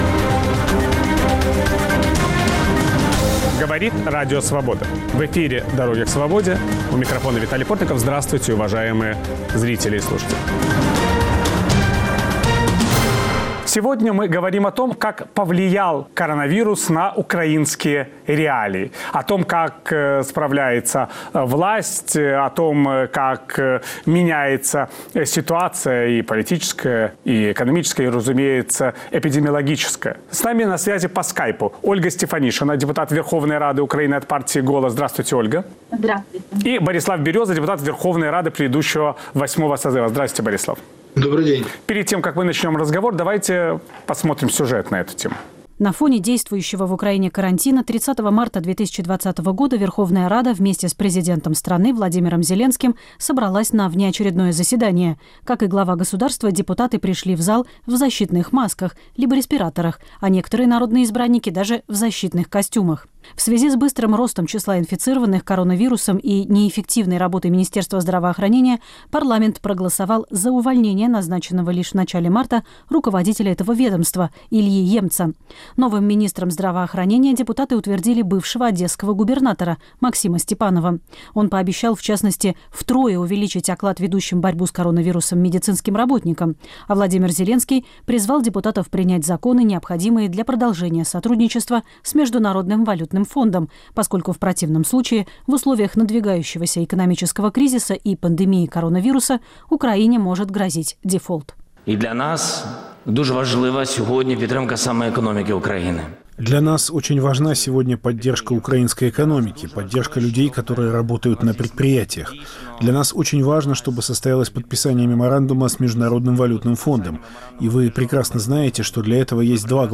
Ведёт программу Виталий Портников.